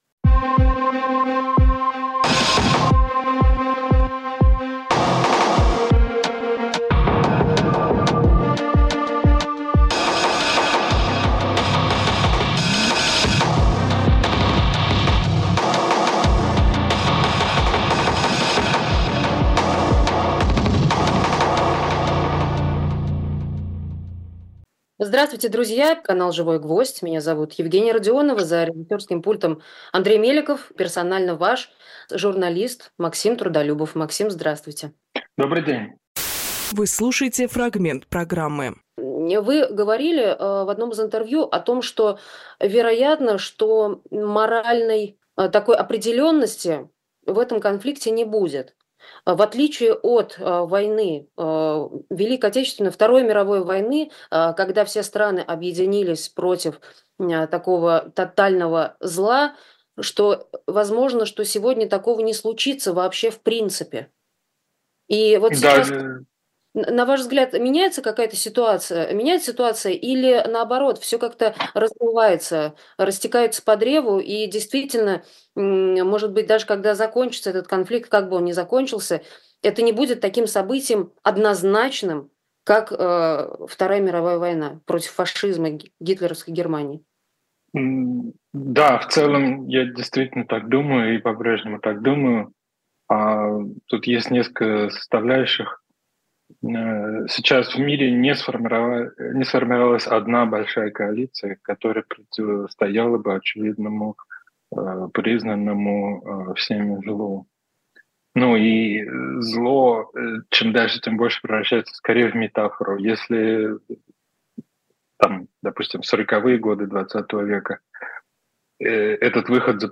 Фрагмент эфира от 28.05.24